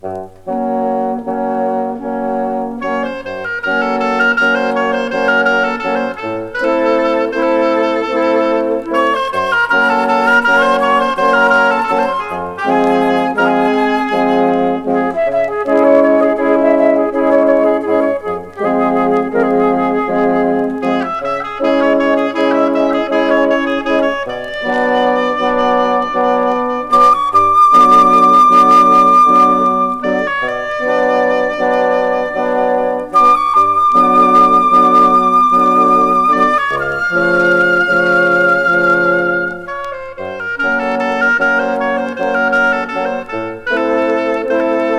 Classical, Stage & Screen　France　12inchレコード　33rpm　Stereo